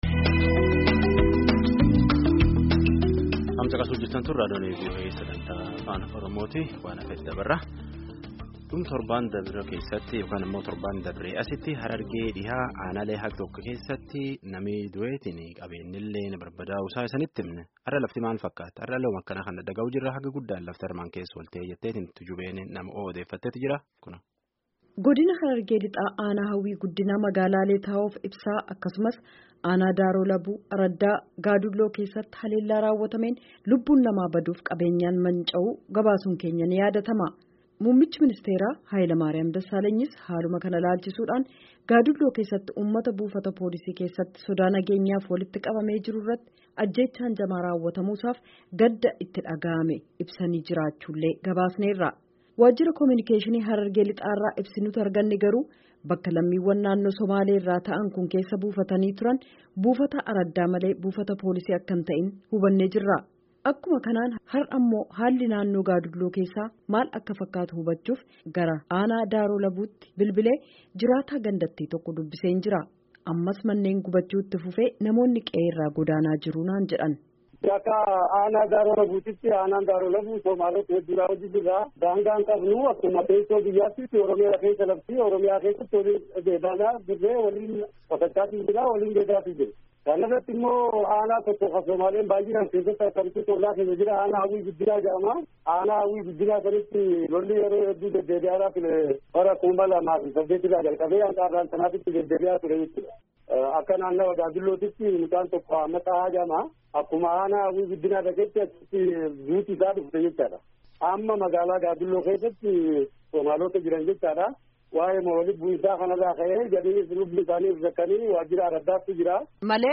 Akkuma kanaan har’as haalli naannoo Gaadulloo jiru maal akka fakkaatu hubachuuf gara aanaa Daaroo Labuutti bilbilee jiraataa gandattii tokko dubbiseen jira. Ammas manneen gubachuun itti fufee namoonni qe’ee irraa godaanaa jiru naan jedhan.